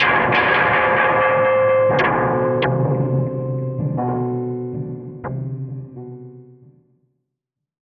Index of /90_sSampleCDs/Transmission-X/One Shot FX
tx_fx_fallonapiano.wav